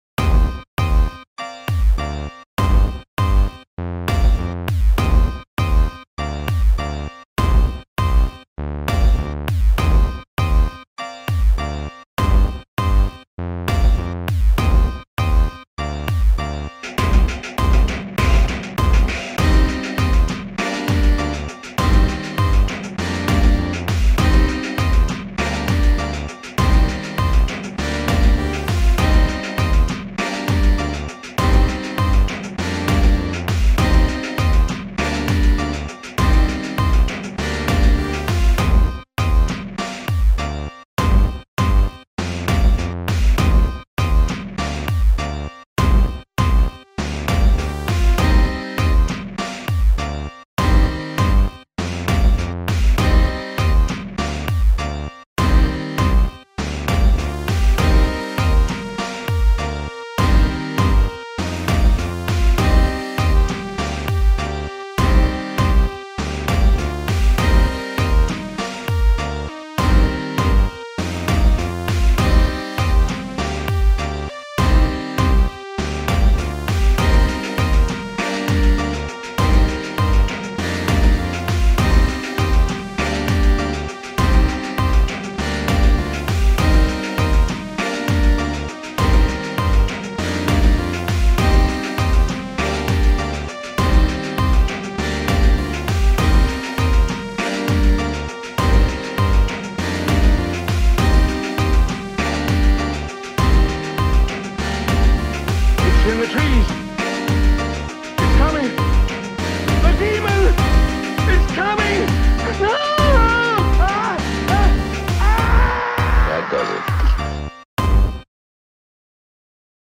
Within this post there are two tracks, both are RAP BEATS, Merry Christmas:
This beat was also made on Reason.Â  It is a combination of hard edged synth stabs, piercing drums, and adrenaline-pumped fully fledged Bruce SpringsteenÂ piano samples.Â  It also includes a lineÂ from theÂ film 'Night ofÂ the Demon.'
Filed under: Instrumental | Comments (2)
I love the distortion drums on doubleyeti! and the piano that's kind of staccato it's sharp and fuzzy at the same time, nice work!